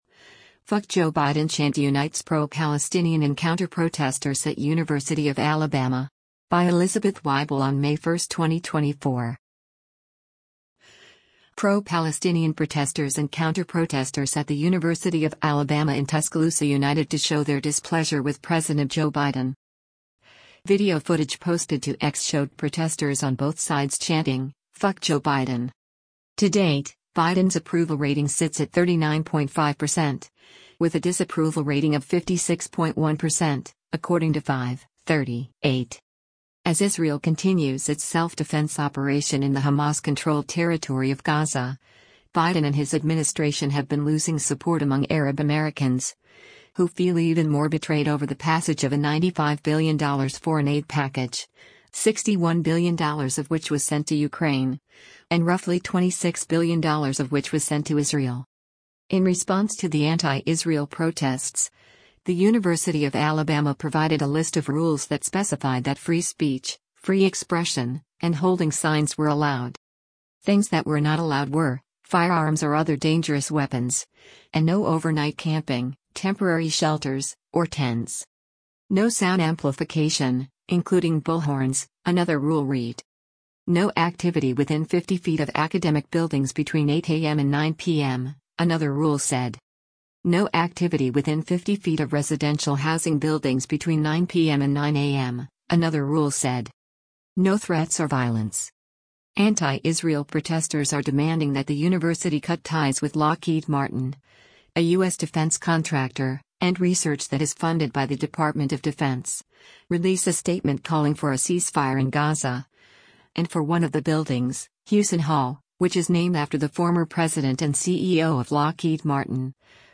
Pro-Palestinian protesters and counter-protesters at the University of Alabama in Tuscaloosa united to show their displeasure with President Joe Biden.
Video footage posted to X showed protesters on both sides chanting, “F*ck Joe Biden.”